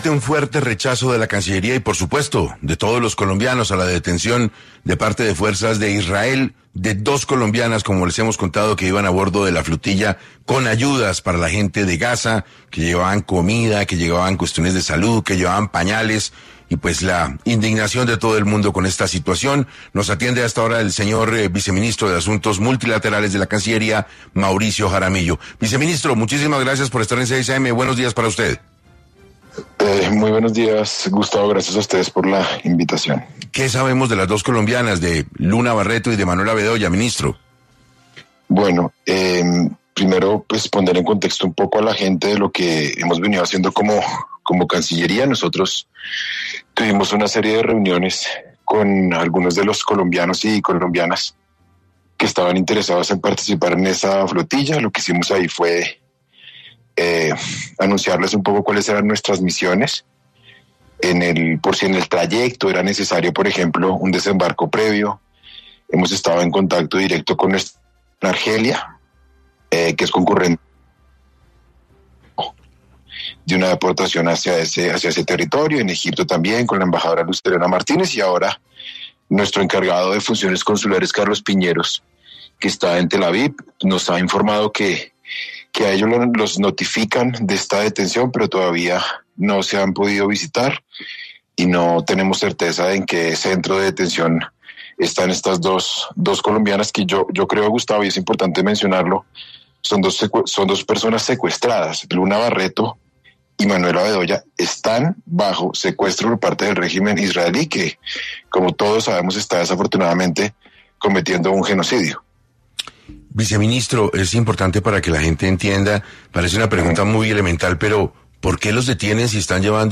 Mauricio Jaramillo Jassir, viceministro de Asuntos Multilaterales de la Cancillería, rechaza en 6AM la detención por fuerzas israelíes de dos colombianas que iban en la flotilla con ayuda humanitaria.